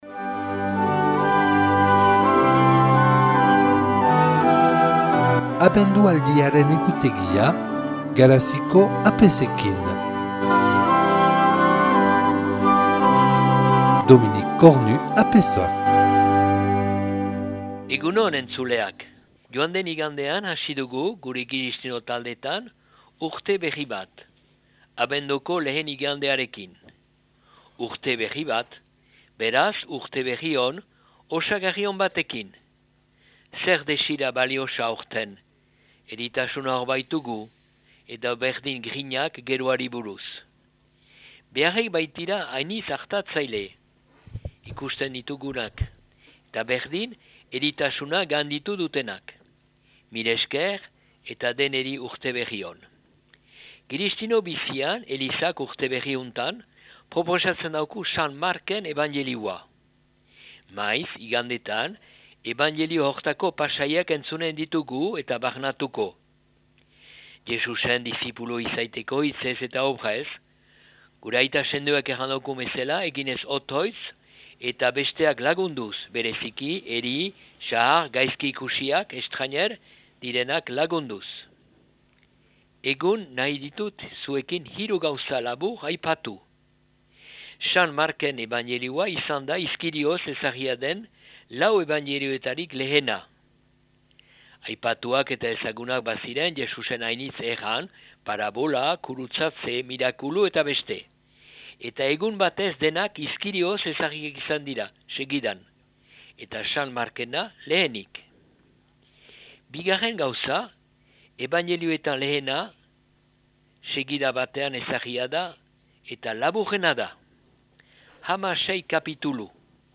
Les prêtres de la Paroisse ont un billet quotidien sur Radio Lapurdi en Basque. Diffusion du message de l'Avent, chaque jour à 7h25, 12h25, 15h10 et 20h25